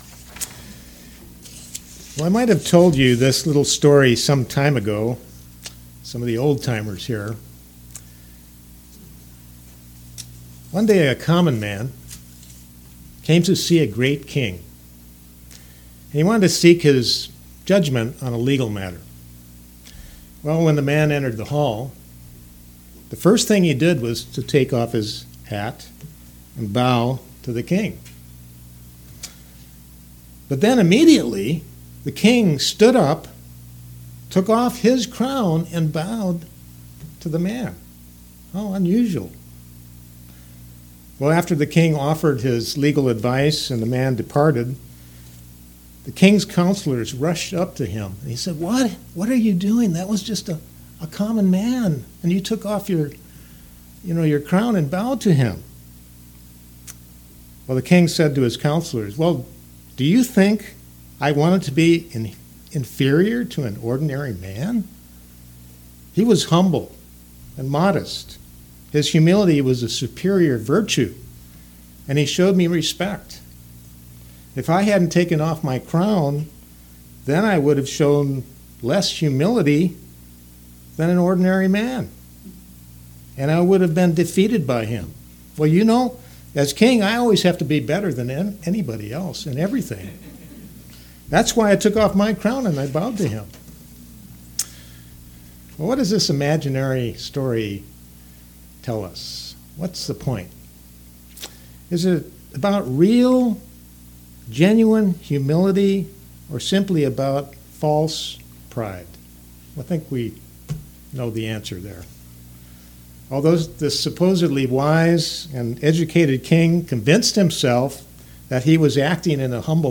Sermons
Given in Knoxville, TN